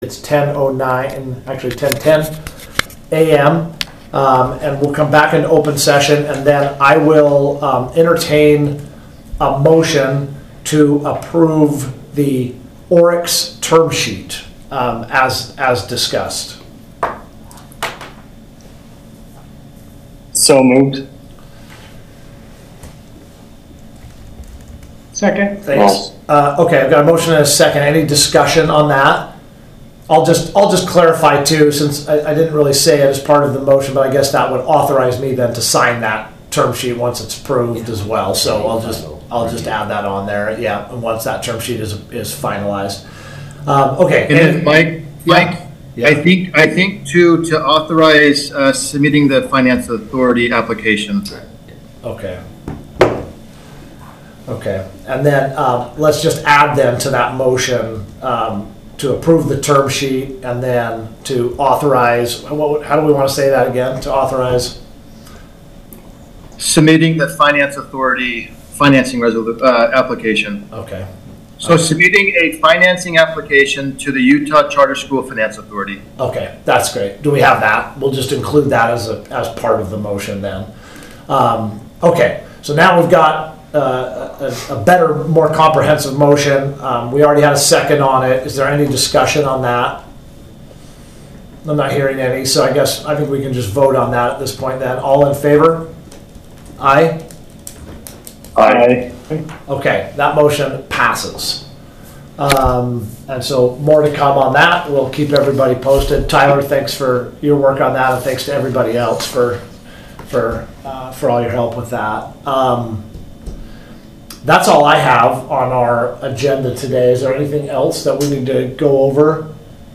Ascent Academies of Utah Board of Directors Meeting Date: March 18, 2024 Time: 9:00AM Anchor Location: 290 N. Flint Street; Kaysville, UT 84037 This meeting of the board of directors will be held electronically.
One or more board members may participate electronically or telephonically pursuant to UCA 52-4-207.